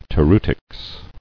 [to·reu·tics]